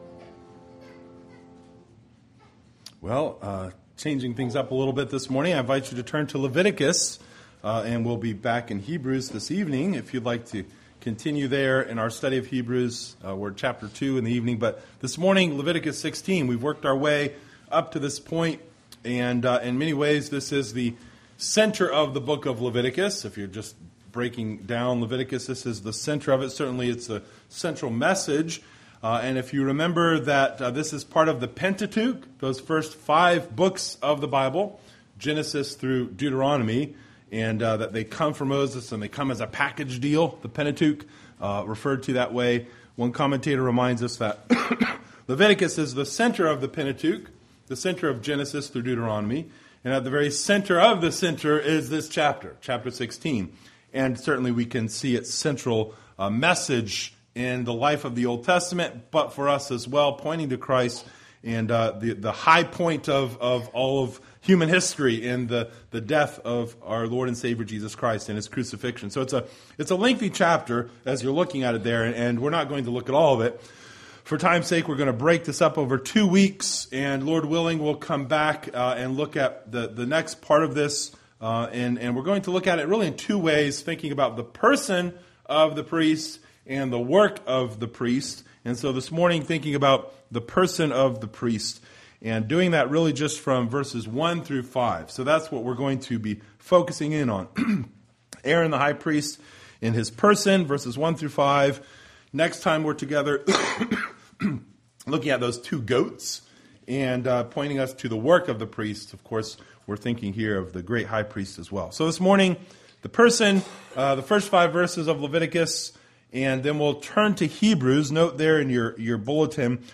9:23-24 Service Type: Sunday Morning Related « Unclean!